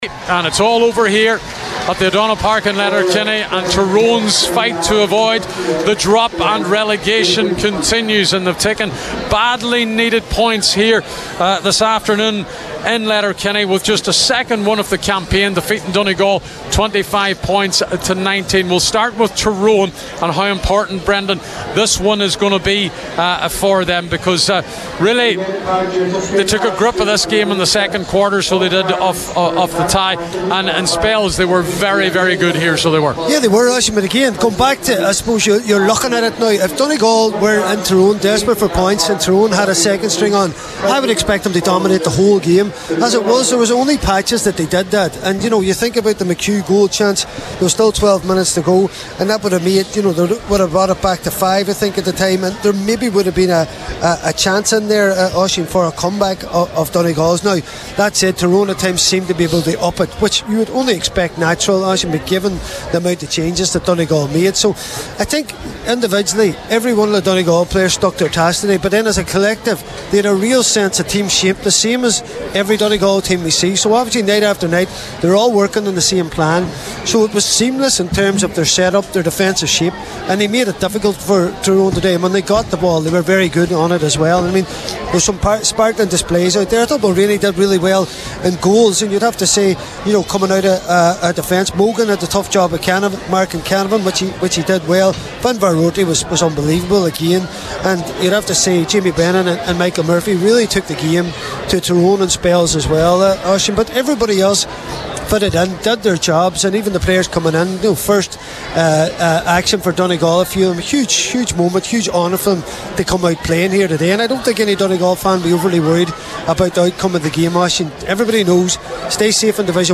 Donegal are beaten by Tyrone in Letterkenny – Post-match reaction